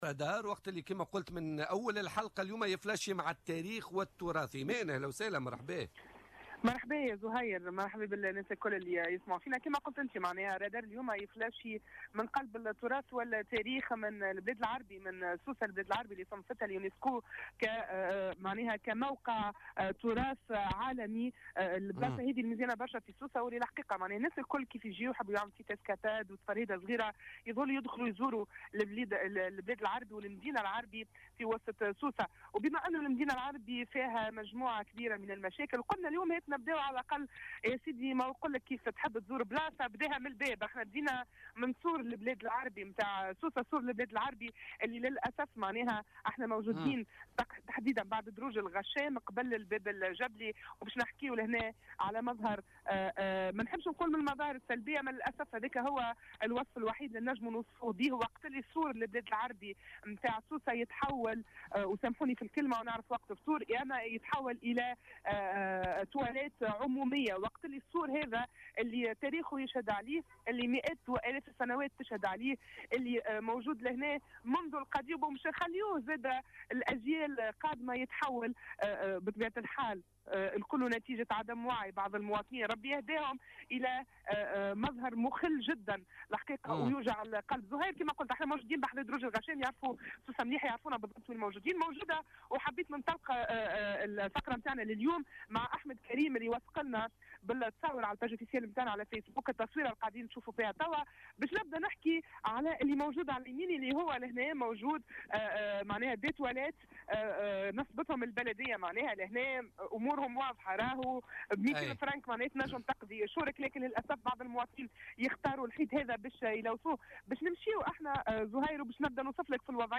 تنقل الرادار اليوم الثلاثاء 21 فيفري 2017 إلى المدينة العتيقة بسوسة لرصد جملة من الإشكاليات التي تعاني منها هذه المنطقة .